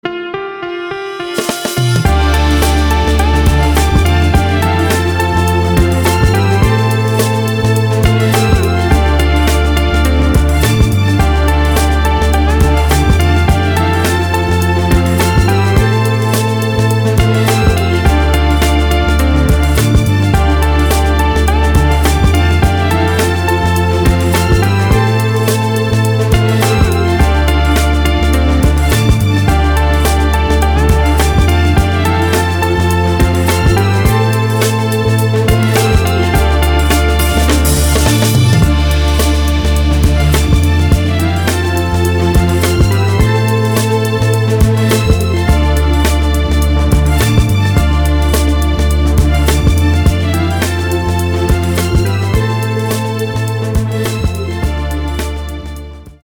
красивые
dance
спокойные
без слов